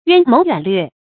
淵謀遠略 注音： ㄧㄨㄢ ㄇㄡˊ ㄧㄨㄢˇ ㄌㄩㄝˋ 讀音讀法： 意思解釋： 深謀遠略。